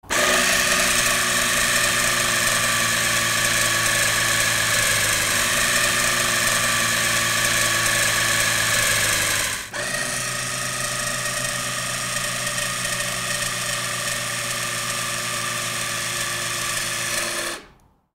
Освежающий звук Jetpik JP200 travel